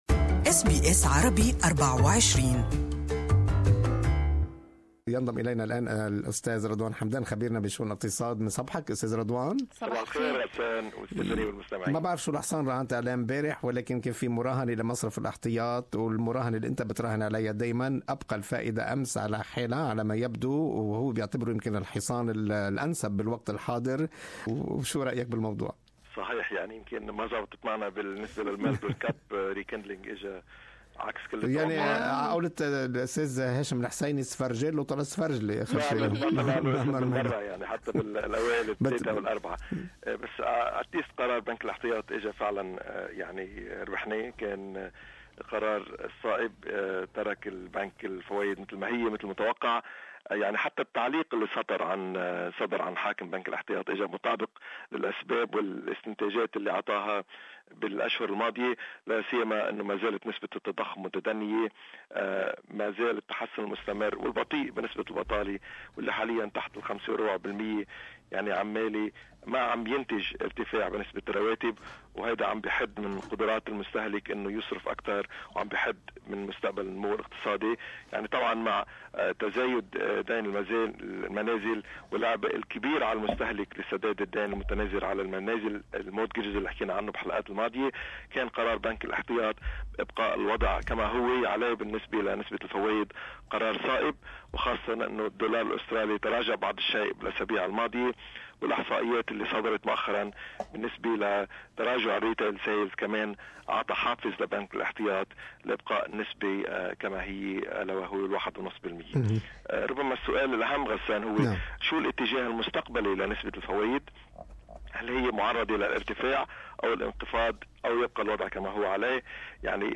Weekly Economic Report